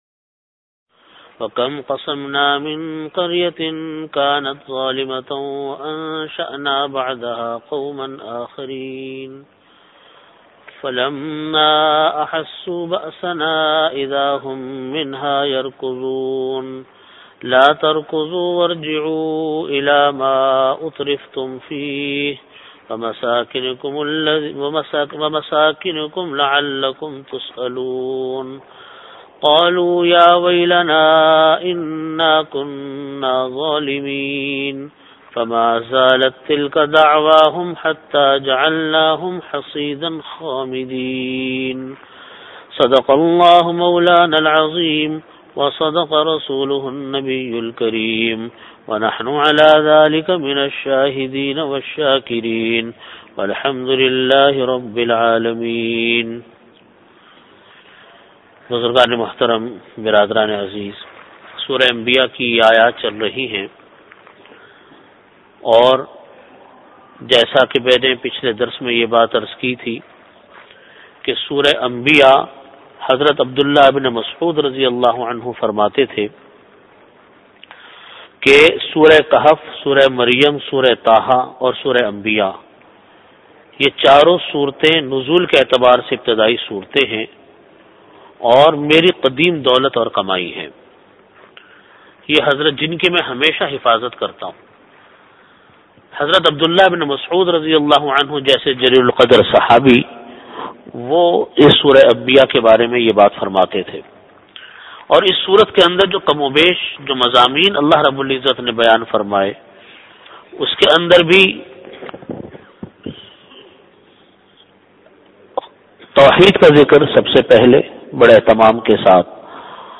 Bayanat
After Asar Prayer Venue: Jamia Masjid Bait-ul-Mukkaram, Karachi